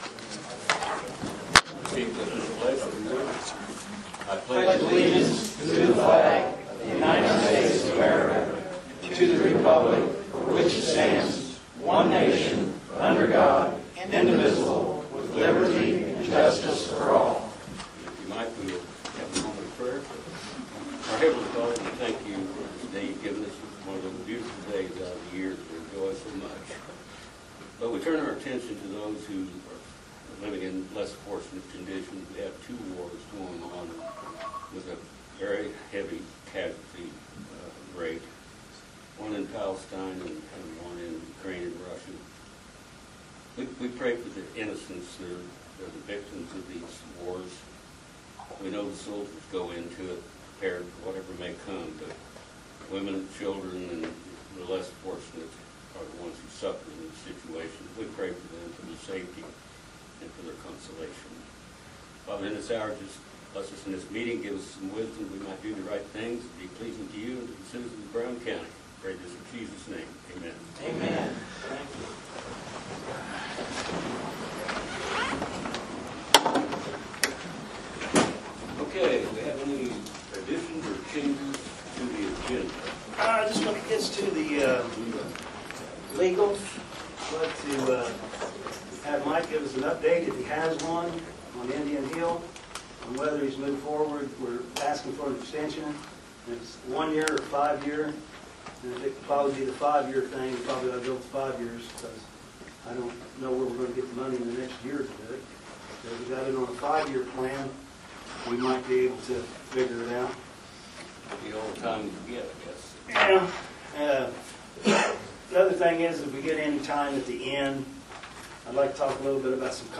AUDIO of the Meeting SPOT Zoning.
A newer resident (25:30) in the area stated he moved here for peace and quiet and wants to keep things that way.